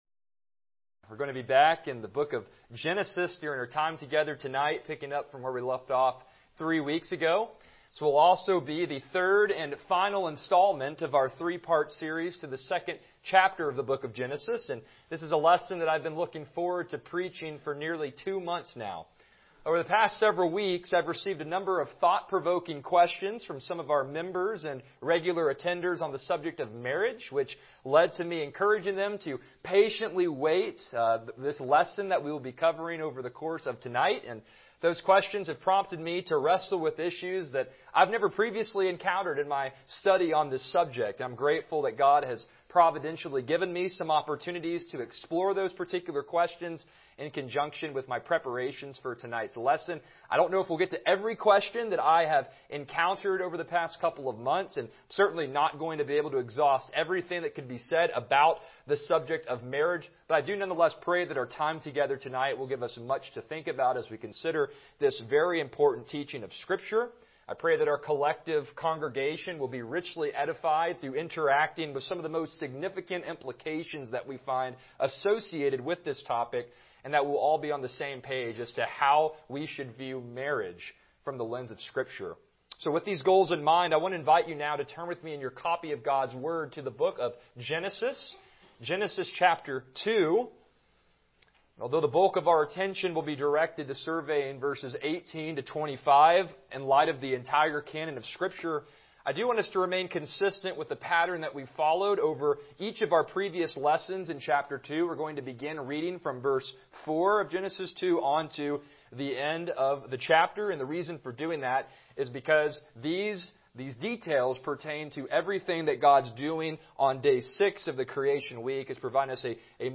Passage: Genesis 2:18-25 Service Type: Evening Worship